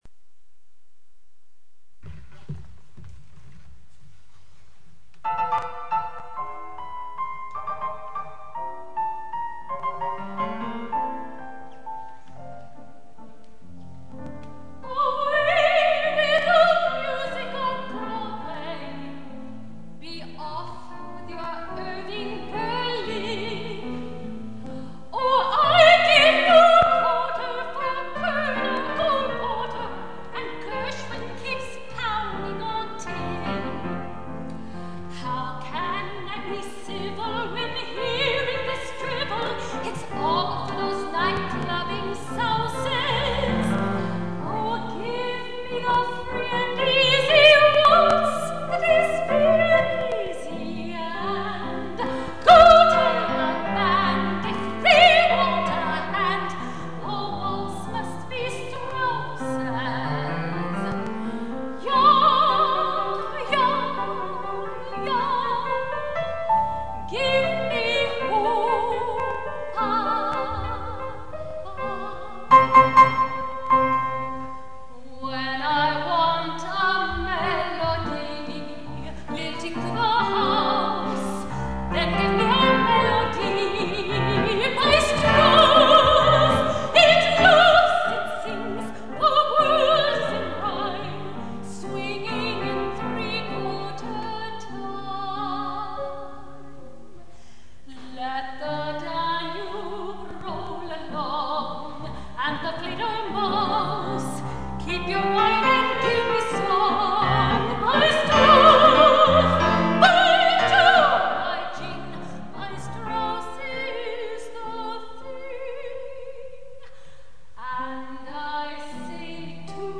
soprano
au piano